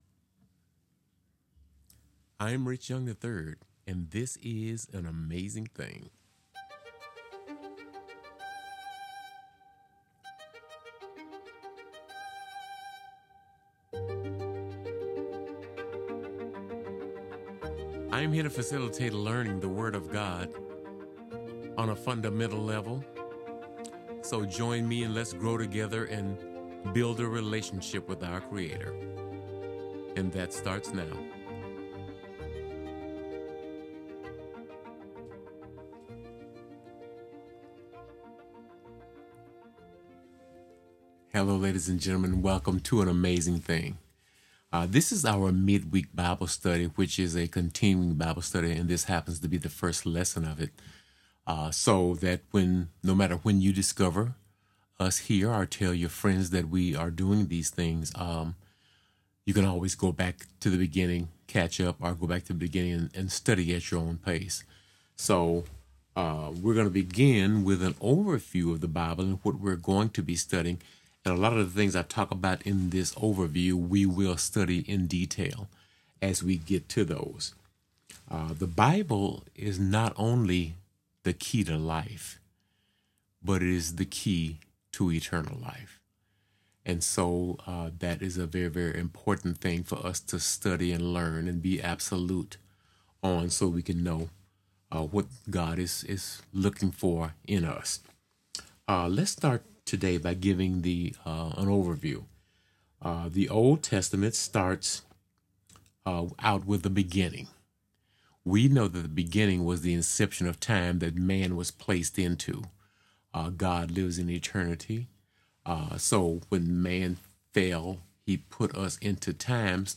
The Beginning | Midweek Bible Study